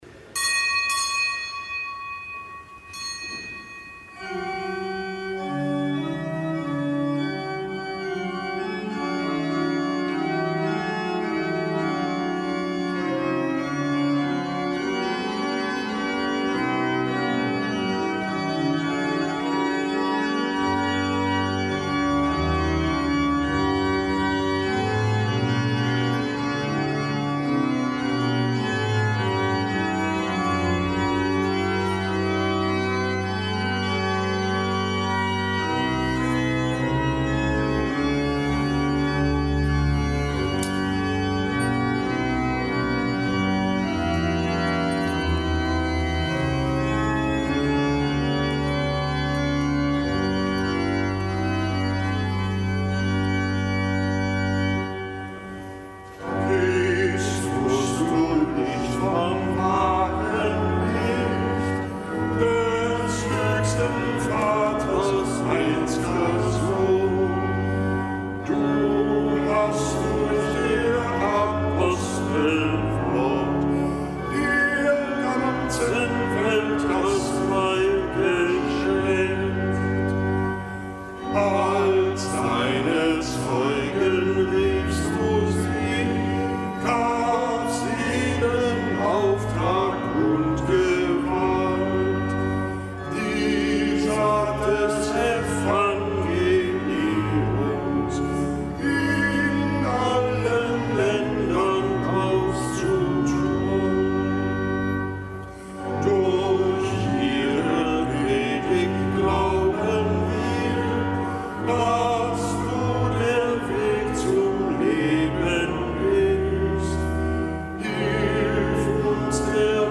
Kapitelsmesse aus dem Kölner Dom am Fest der Bekehrung des heiligen Apostels Paulus.